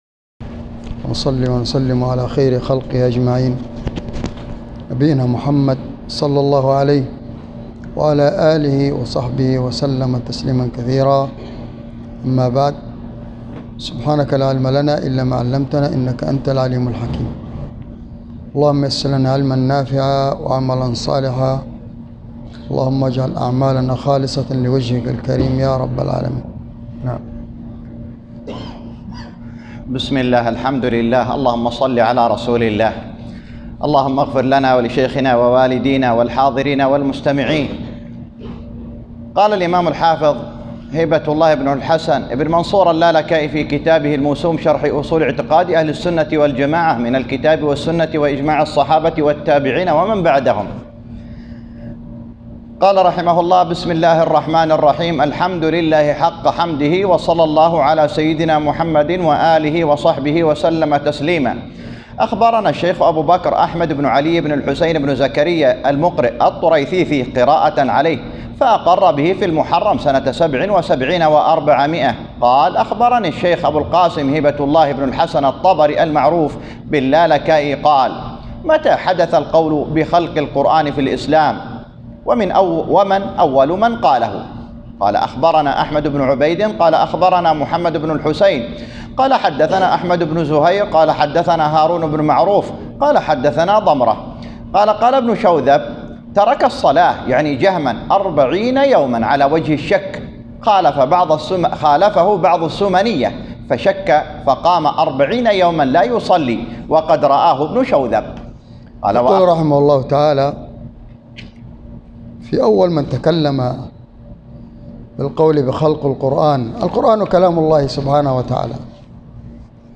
شرح أصول اعتقاد اهل السنة والجماعة الامام الحافظ اللالكائي والمقام بجامع الخير في ابوعريش